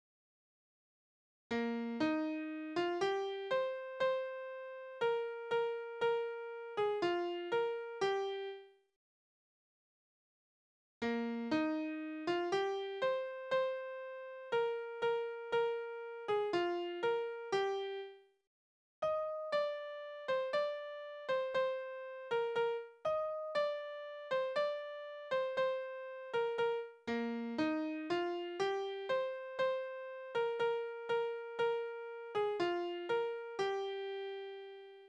Soldatenlieder: Der junge Soldat in Frankreich
Tonart: Es-Dur
Taktart: 4/4
Tonumfang: Oktave, Quarte
Besetzung: vokal